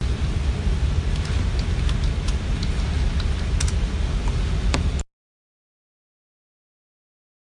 鼠标点击
描述：点击几下鼠标！ （当然是用备用鼠标制作的。）
标签： 单击 按下 按钮 点击 鼠标点击
声道立体声